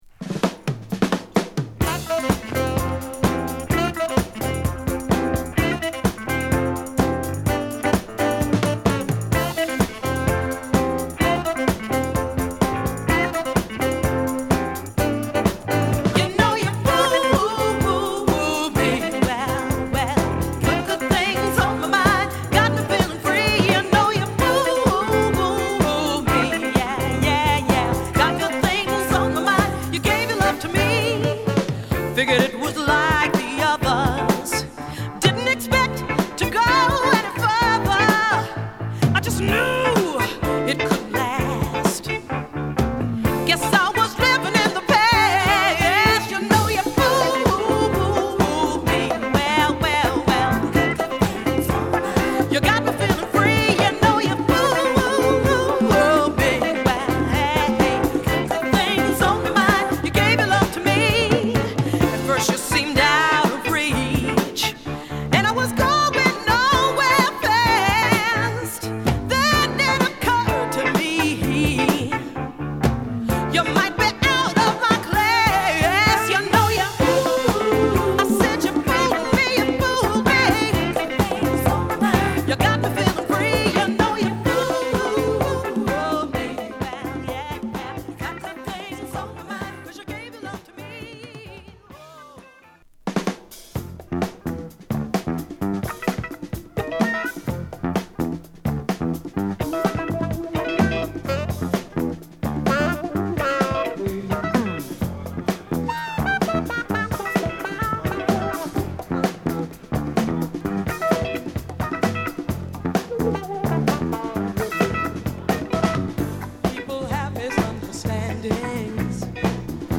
でのライブ録音盤